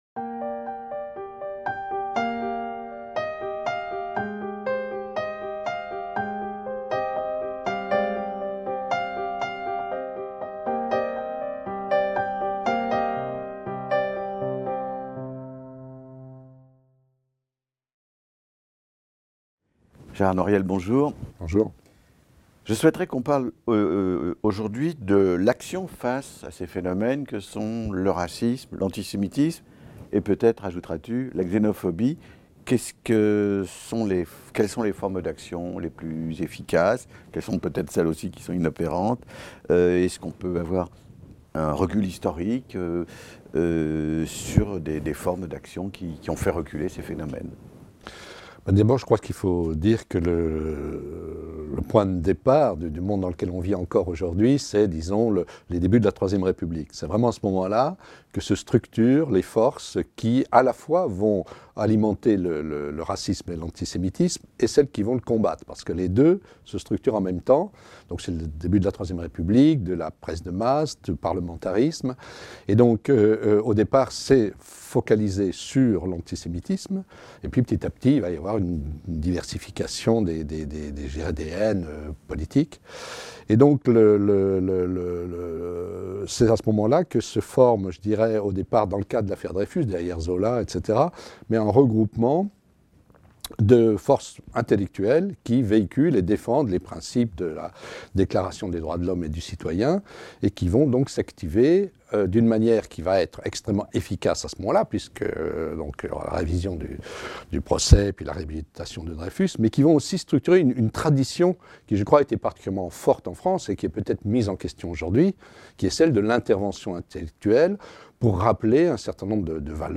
Le rôle des intellectuels et des artistes dans la lutte contre le racisme et l'antisémitisme - Un entretien avec Gérard Noiriel | Canal U